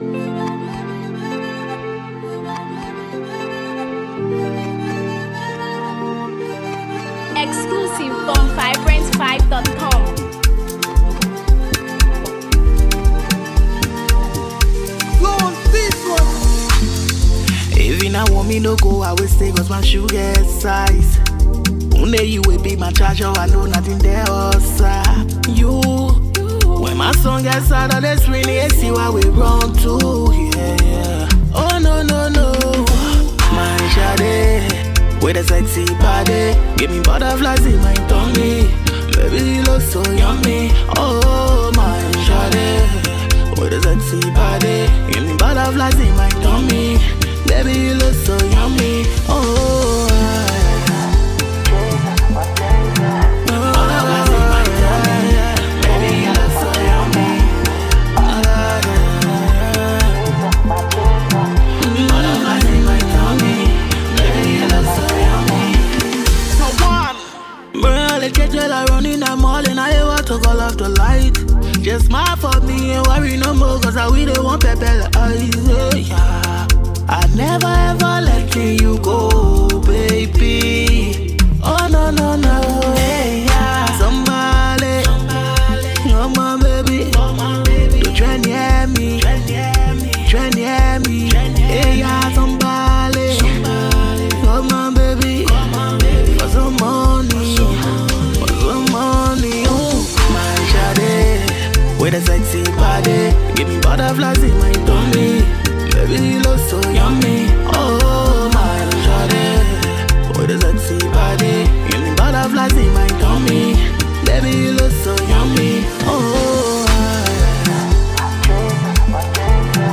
a mesmerizing blend of soulful melodies and evocative lyrics
The song’s soothing melodies and introspective lyrics